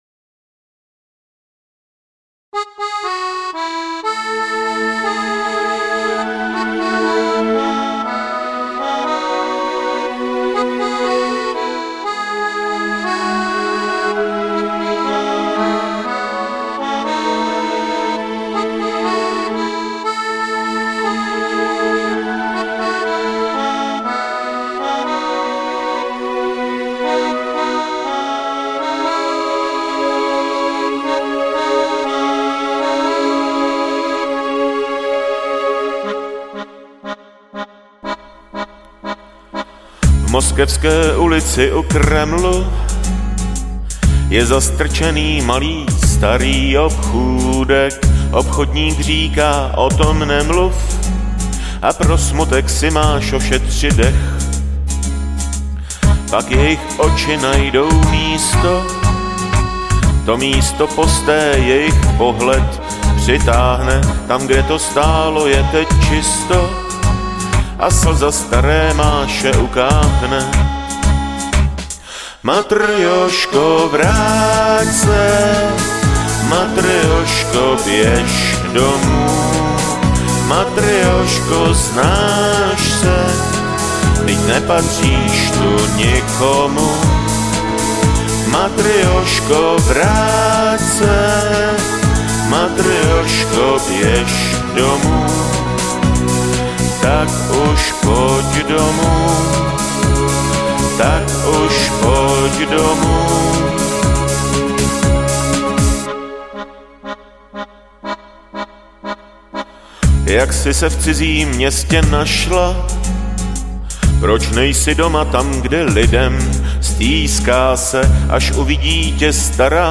Žánr: Pop
písničkáře s notebokem.